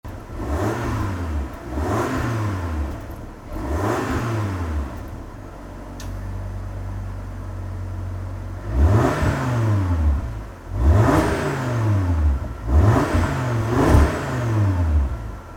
Listen to the 5-Cylinder Symphony of this RS3!
• RS Sports Exhaust System with Black Oval Tips
• 2.5TFSI 5-Cylinder Turbo Engine (400PS & 500NM)
audi-rs3-8y-vorsprung-sportback-kemora-grey-xef-Revs.mp3